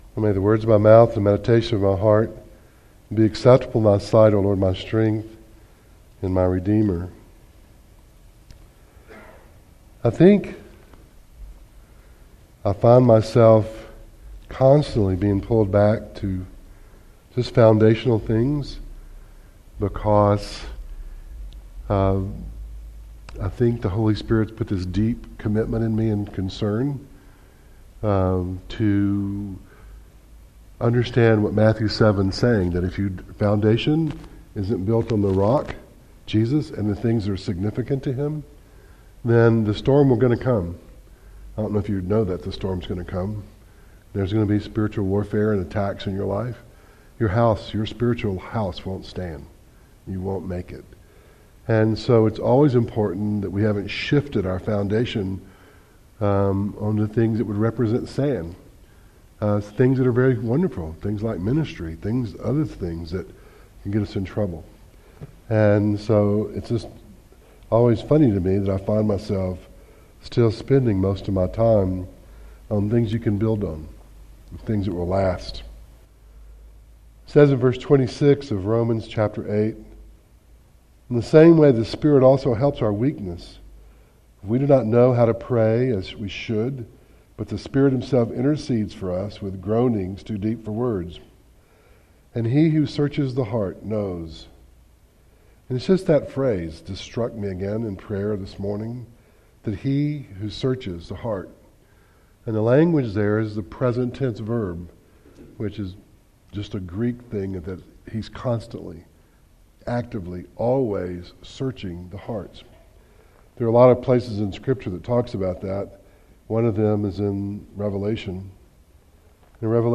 Audio Devotionals